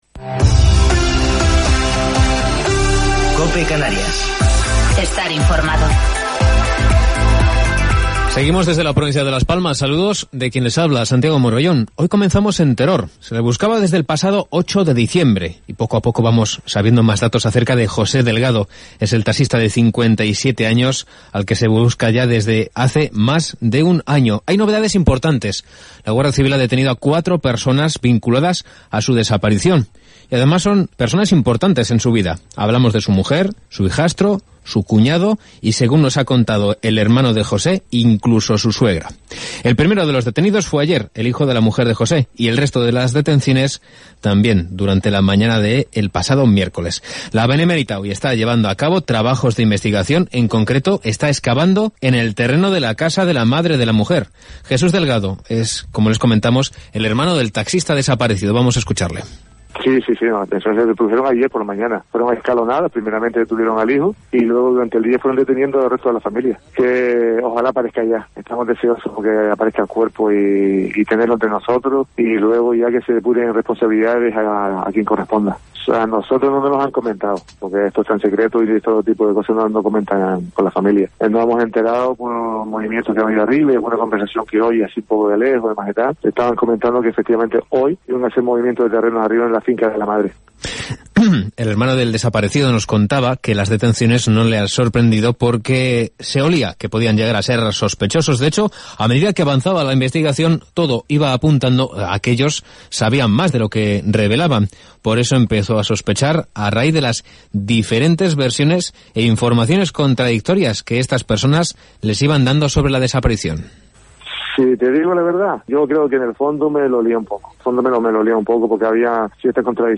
Informativo local 24 de Septiembre del 2020